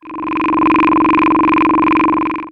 Machine20.wav